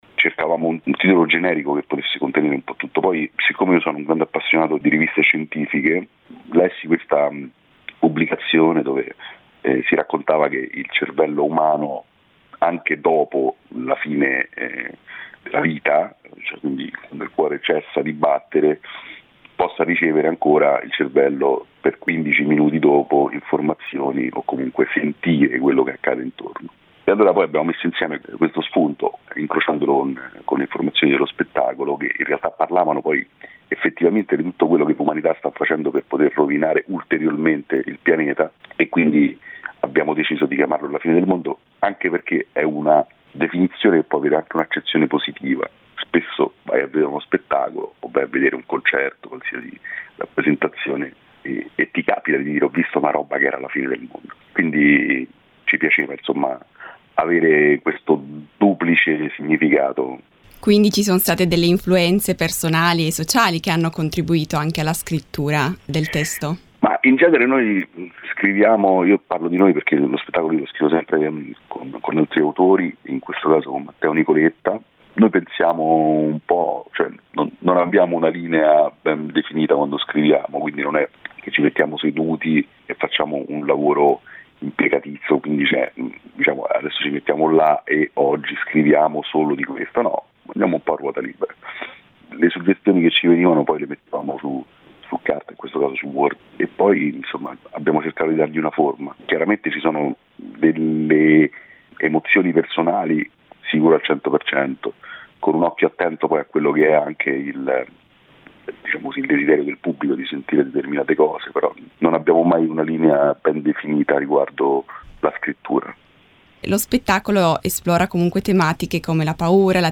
Le parole di Andrea Perroni, intervistato